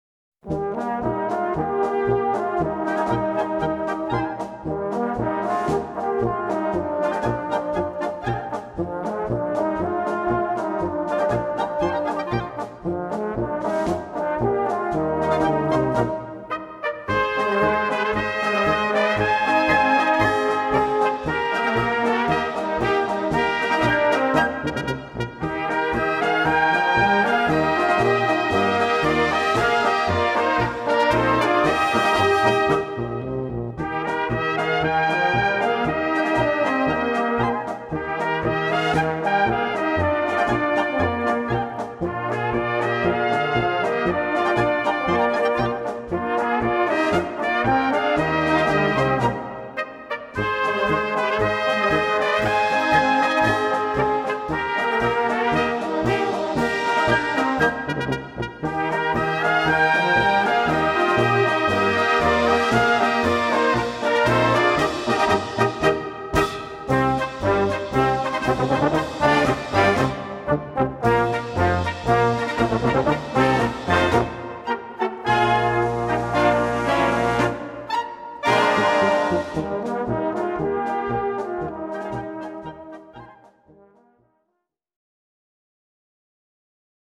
Gattung: Böhmische Polka
Besetzung: Blasorchester